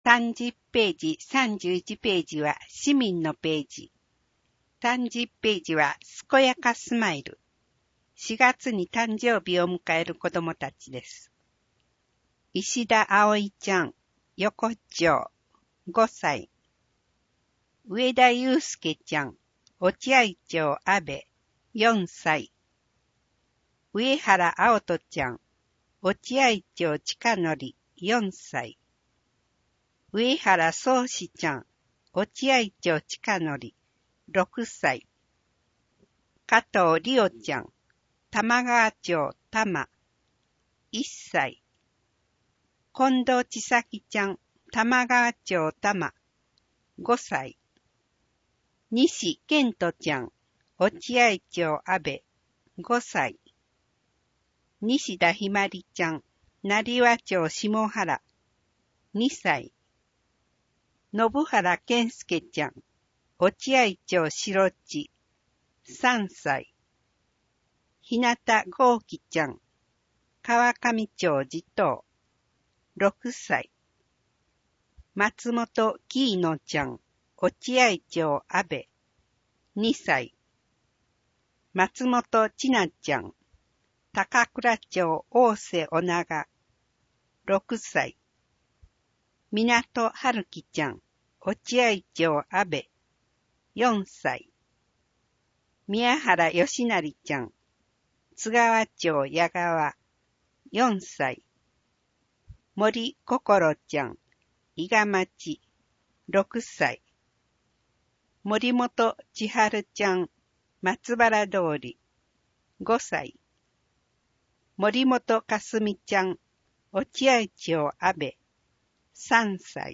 声の広報　広報たかはし4月号（246）